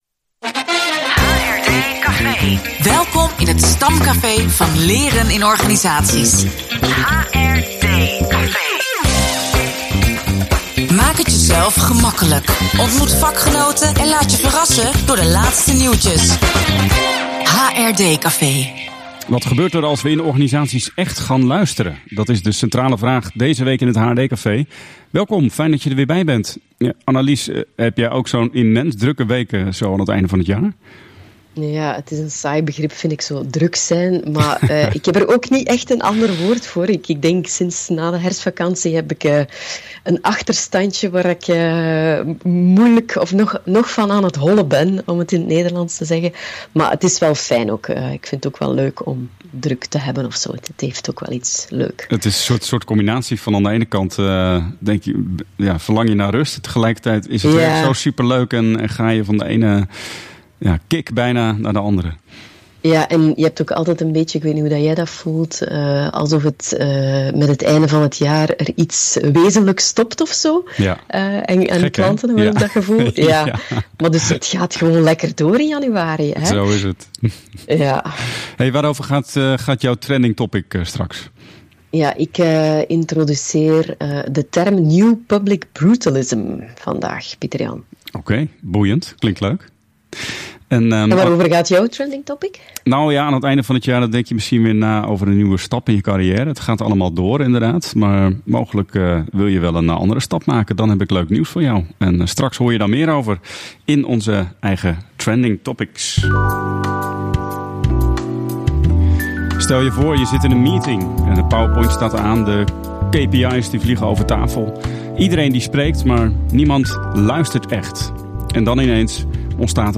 Het gesprek raakt aan leiderschap als uitnodiging, het trainen van de ‘luisterspier’, en het herkennen van je eigen plek als leider in de groep. Ook wordt verkend hoe Appreciative Inquiry en containment kunnen helpen om het luistervermogen van teams te vergroten.